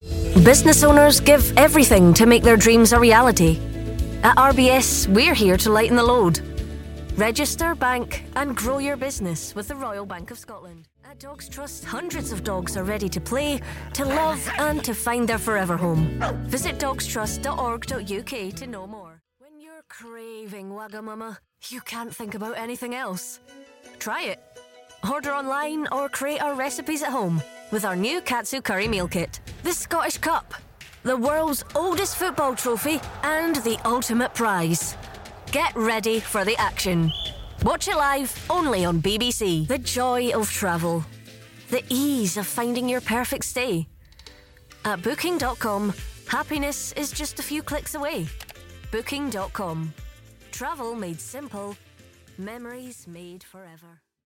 Inglés (escocés)
Conversacional
Corporativo
Confiado